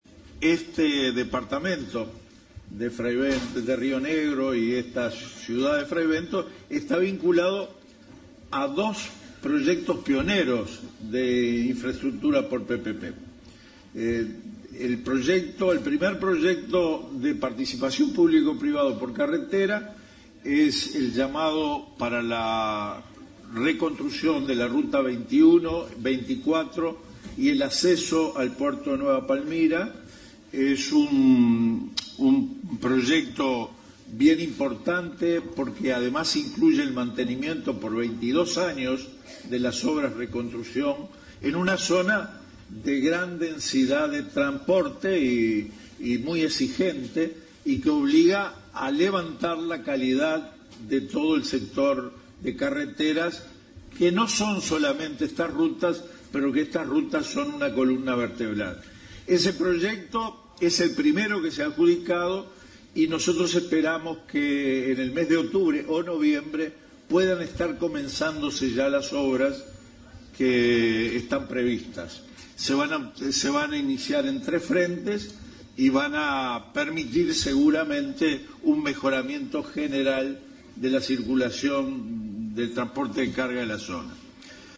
En el marco del Consejo de Ministros abierto realizado este lunes en Fray Bentos, departamento de Río Negro, el ministro de Transporte y Obras Públicas, Víctor Rossi, anunció el comienzo de importantes proyectos de infraestructura con modalidad Público- Privada en la ciudad, para reconstruir las rutas 21, 24 y el acceso al puerto de Nueva Palmira.